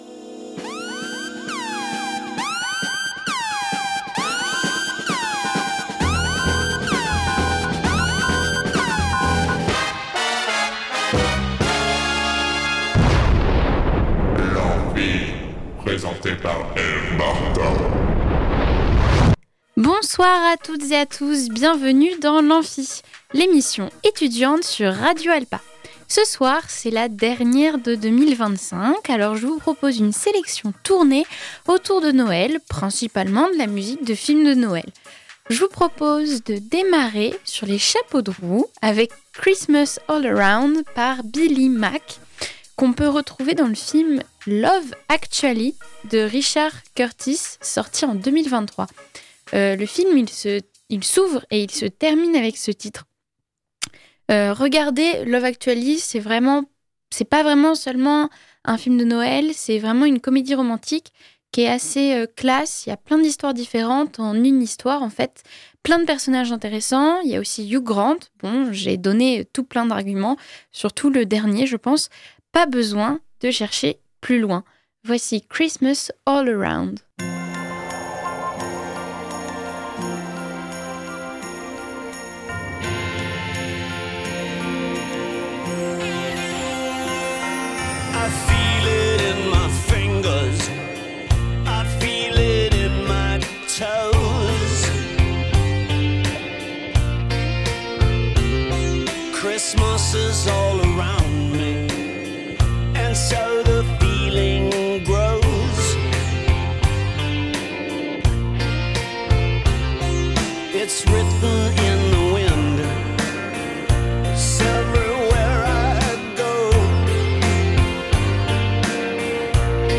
Petite playlist avec de la musique de films de Noël !